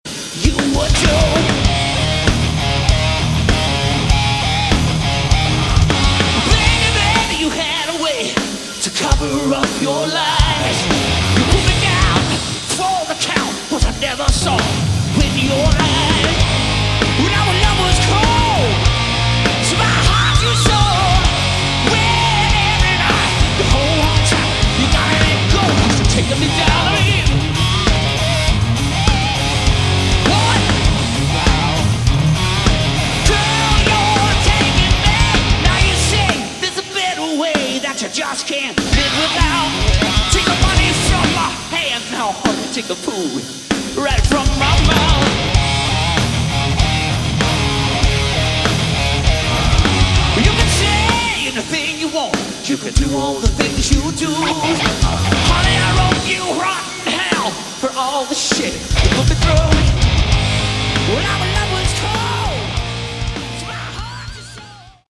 Category: Hard Rock / Melodic Rock
keyboards, vocals
guitar, backing vocals
bass
drums